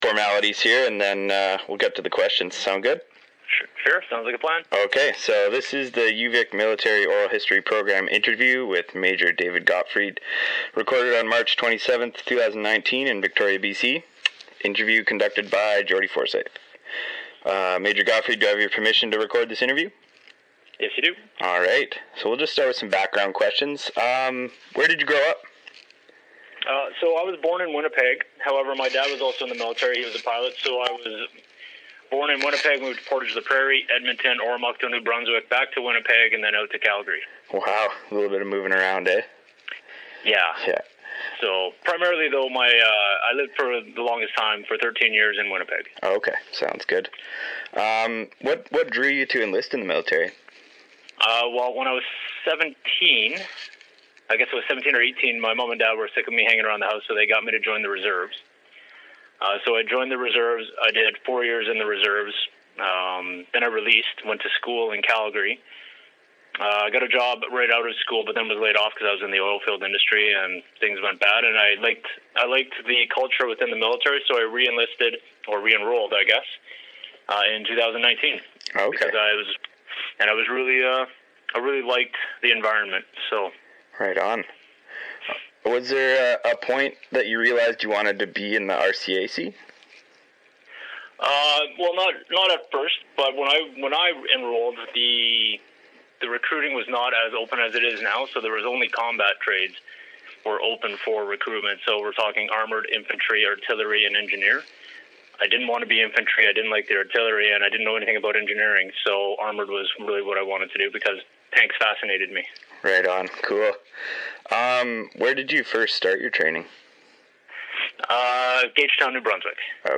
Interview took place on March 27, 2019 in Victoria, B.C.